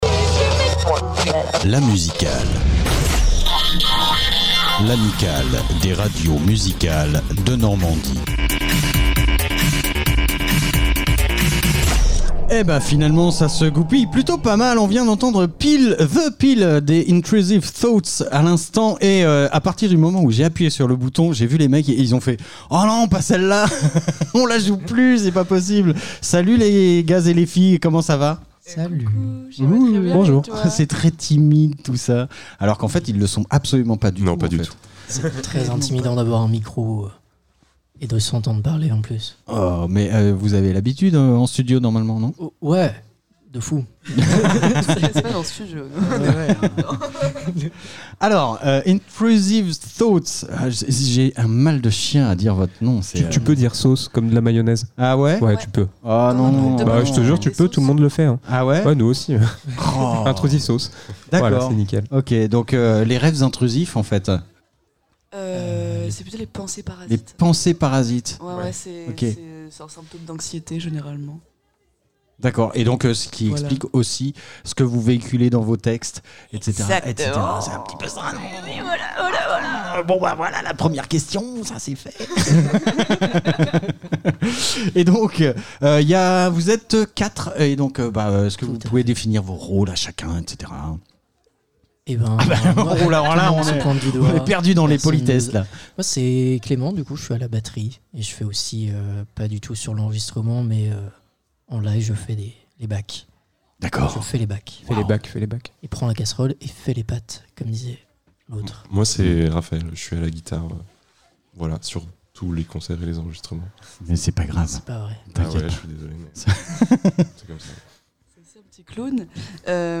Le studio mobil est une caravane entièrement équipée pour produire des émissions couvrant différents événements. On y reçoit les acteurs et participants de ces manifestations pour les interviewer en direct (quand les connexions sont possibles) ou en conditions de direct, ce qui donne de nombreux podcasts à retrouver ici.